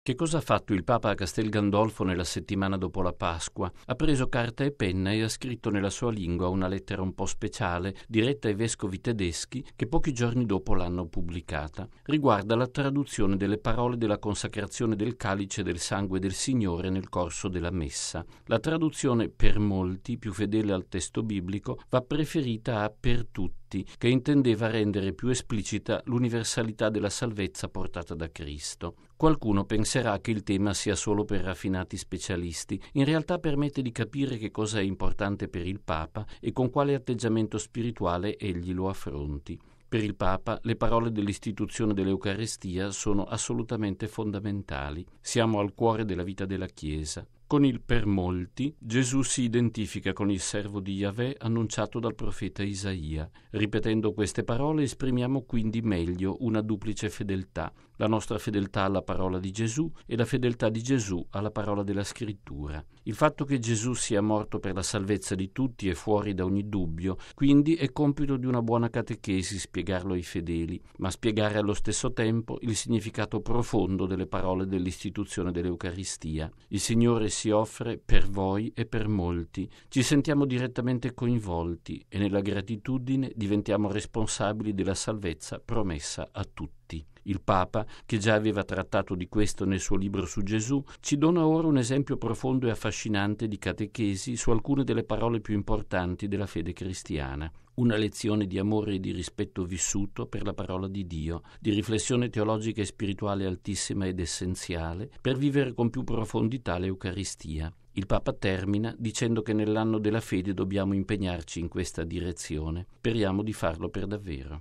"Per molti e per tutti": il Papa chiarisce le parole della Messa. Il commento di padre Lombardi
Una questione teologica ma dai profondi risvolti di fede per ogni cristiano, come ribadisce il nostro direttore generale, padre Federico Lombardi, nel suo editoriale per “Octava dies”, il settimanale d’informazione del Centro Televisivo Vaticano: